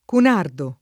[ kun # rdo ]